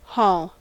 Ääntäminen
US : IPA : /hɔl/ UK : IPA : /hɔːl/